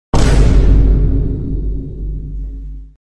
Download Free Vine Boom Sound Effects